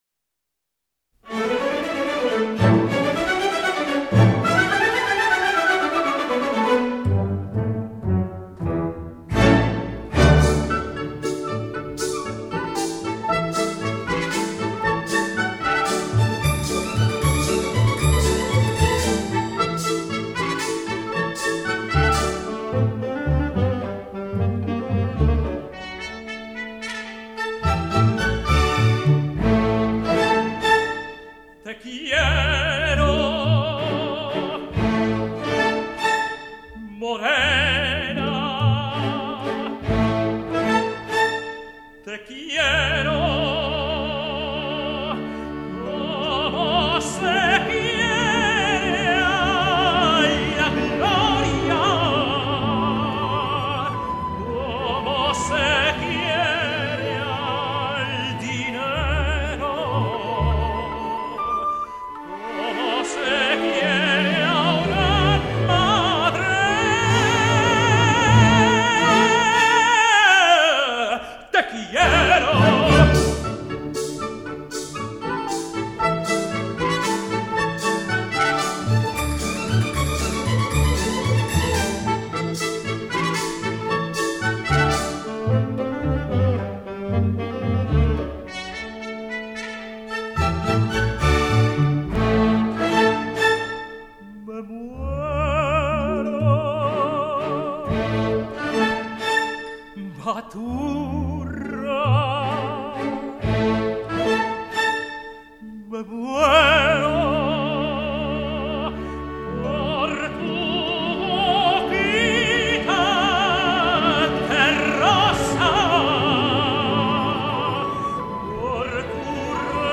zarzuela